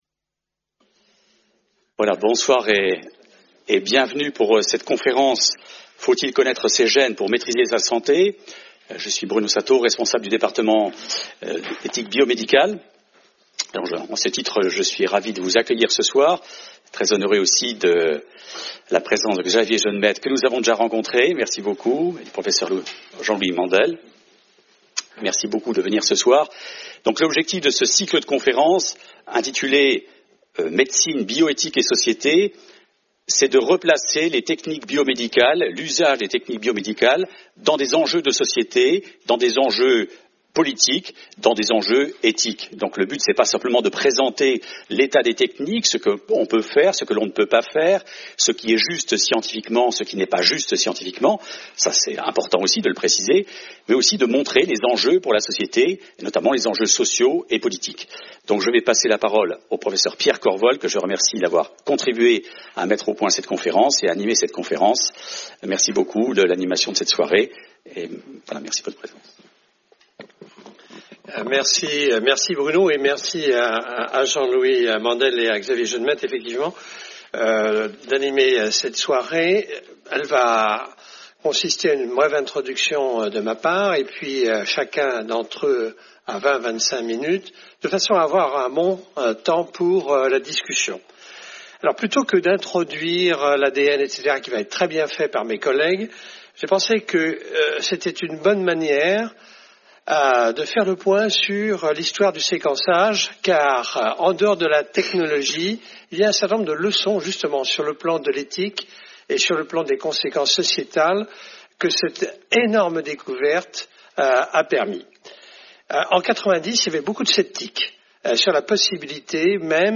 Débat animé par le Pr. Pierre Corvol